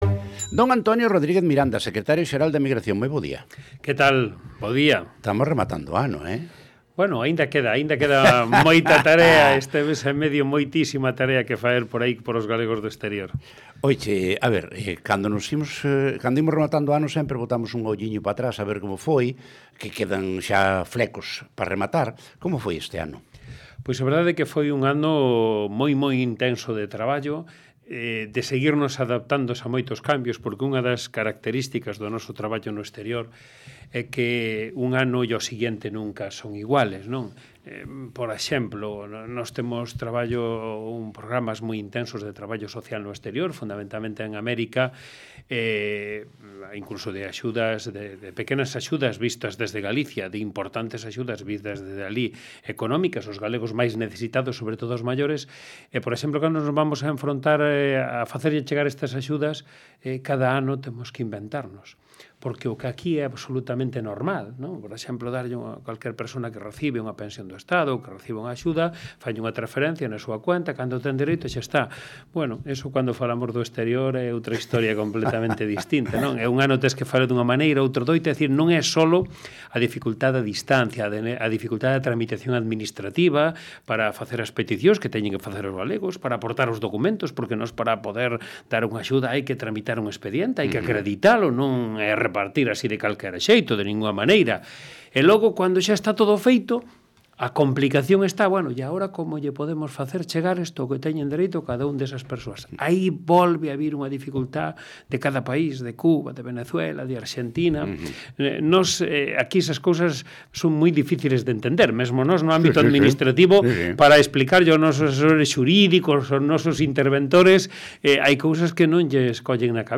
Audio - Entrevista ao secretario xeral da Emigración en esRadio